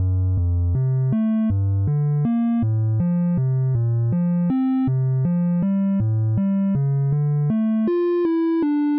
Let's consider this simple bass line that will serve as an example as I go through each step.
basicbassA01.mp3